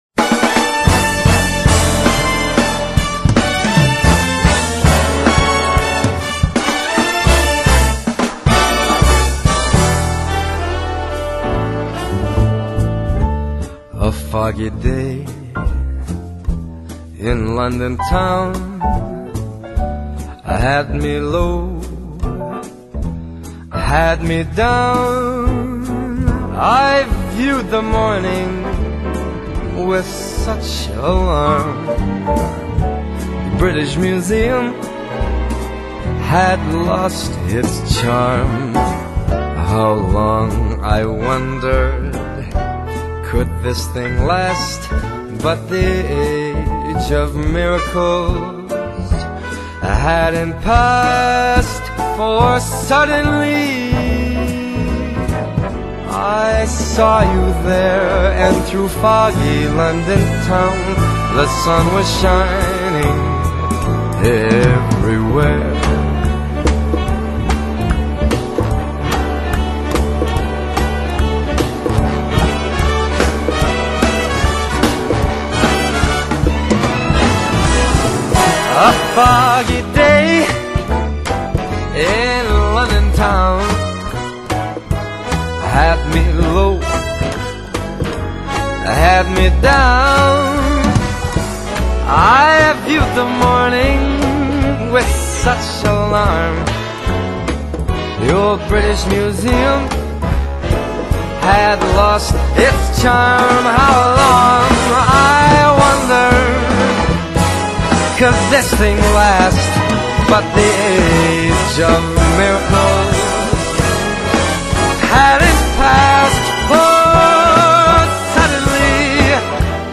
音乐类型：爵士乐
管号乐铺排与华丽编曲